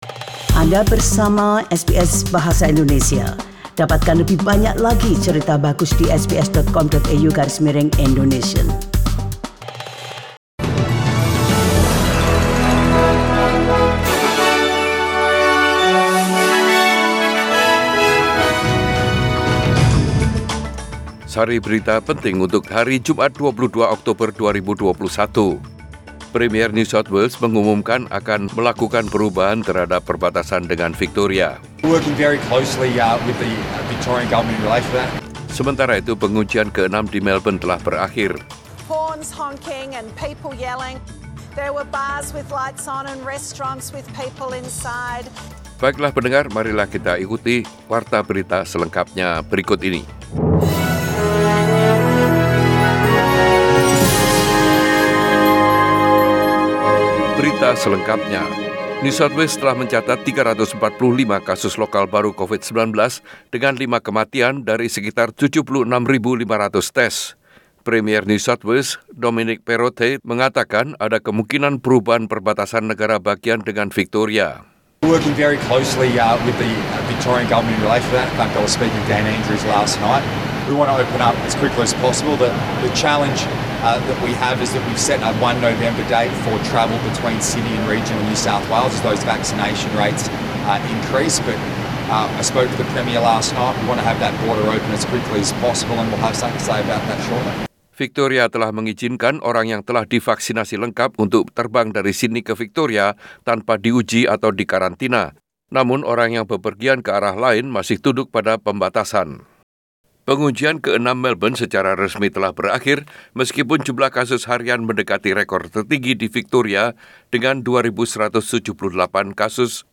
Warta Berita Radio SBS Program Bahasa Indonesia Source: SBS